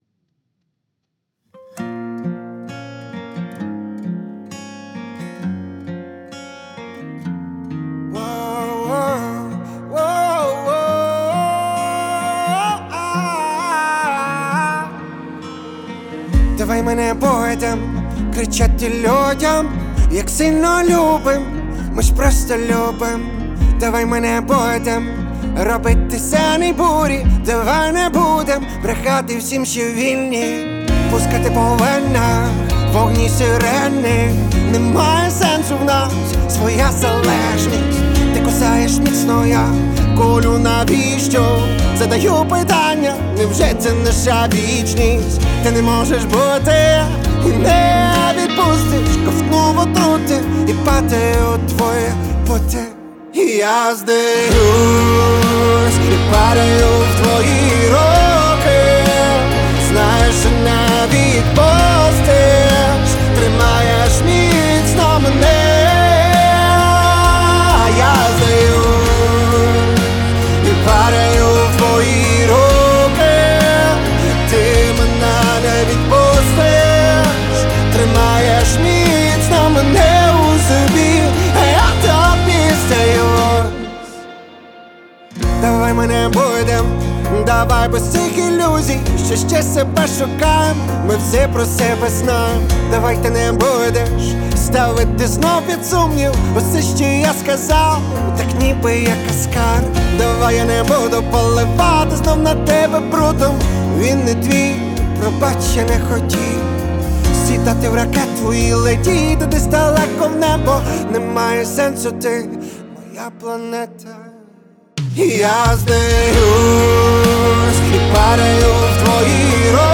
• Жанр: Популярна музика